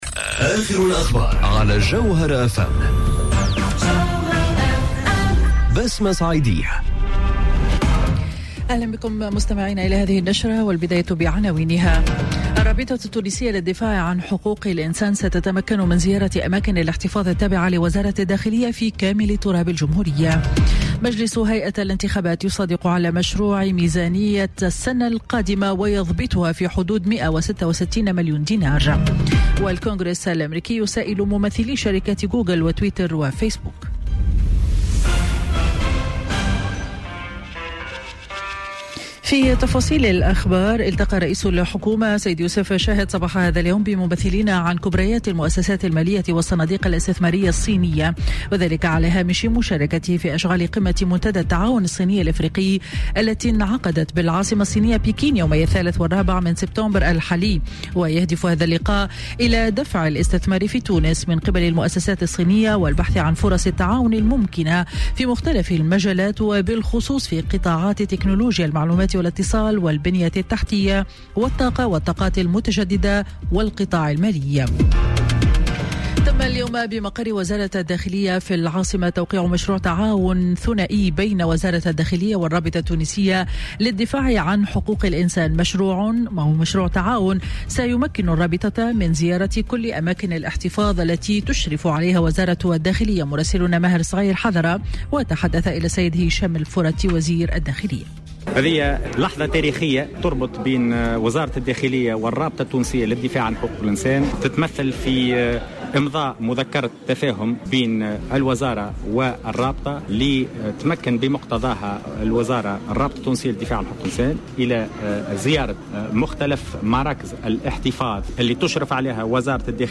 نشرة أخبار منتصف النهار ليوم الإربعاء 5 سبتمبر 2018